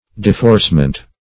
Deforcement \De*force"ment\, n. [OF.] (Law)
deforcement.mp3